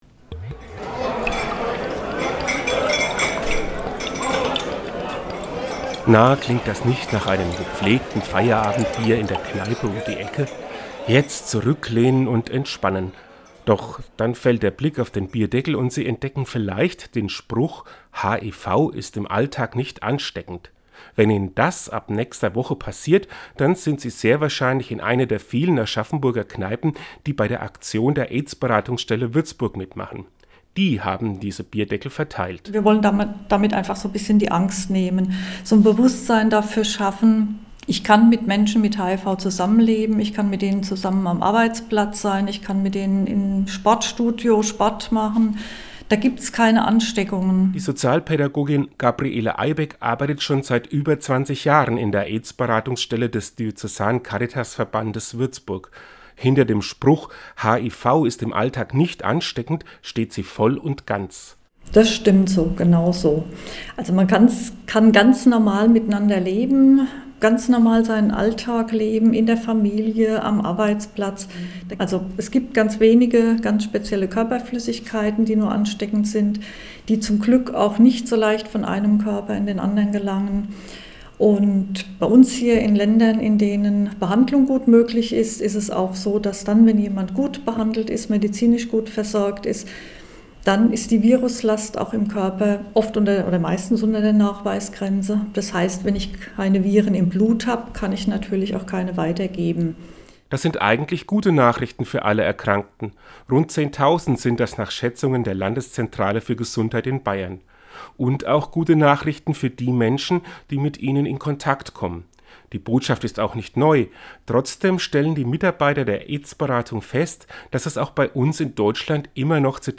Seinen Radiobeitrag hören sie hier!